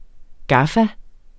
gaffa substantiv, fælleskøn Bøjning -en Udtale [ ˈgɑfa ] Betydninger kortform af gaffatape Rapportér et problem fra Den Danske Ordbog Den Danske Ordbog .